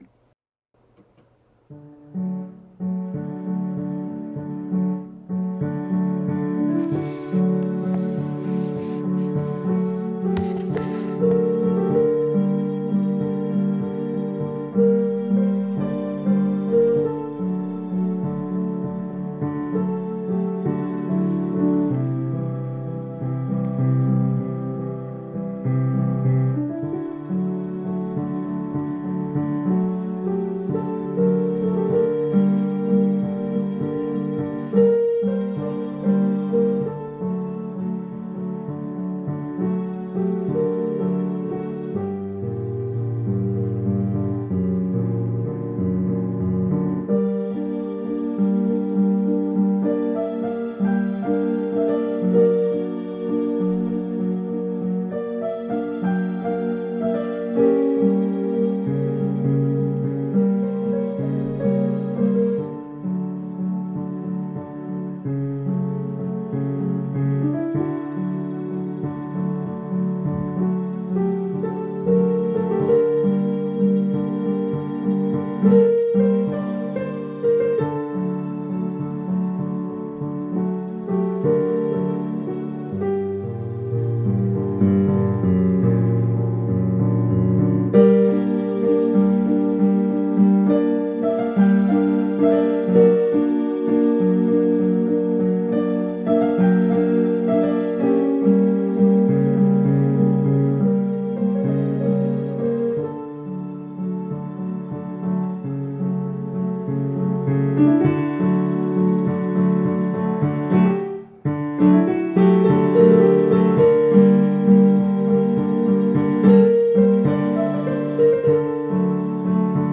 Then as each piece was completed, I would make a recording off of the piano’s playback without using the headphones.
Each was duplicated directly from the piano with a different app and device.
I added background strings throughout the whole piece, although you can't really hear them on this problematic recording.  And also, I do mess up for a couple of seconds near the end, but I picked it up quickly, so I don’t think it’s too bad.
RR-Piano-Compositions-Beguine-editied-.mp3